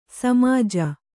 ♪ samāja